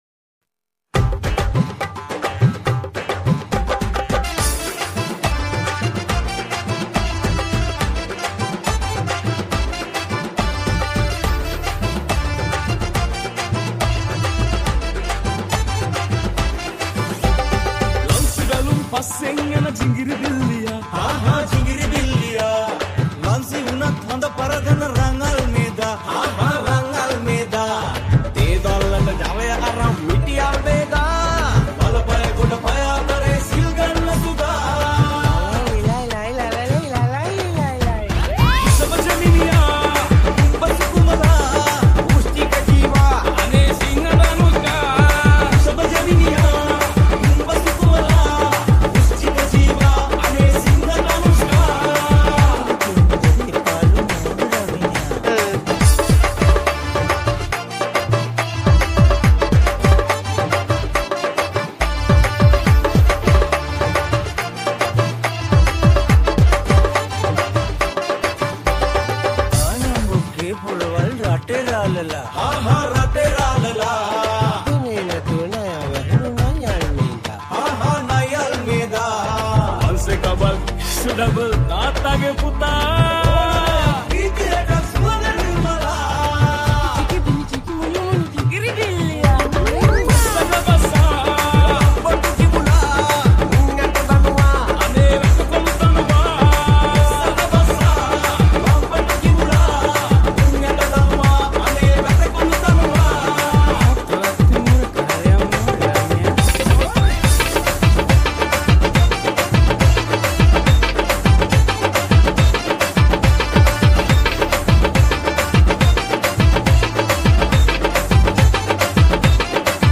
High quality Sri Lankan remix MP3 (2).